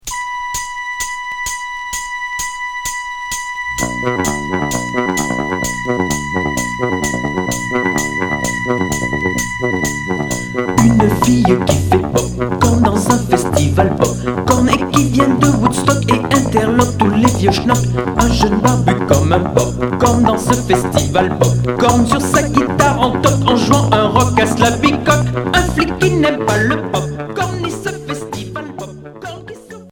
Pop synthétique Premier 45t retour à l'accueil